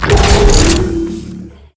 droidic sounds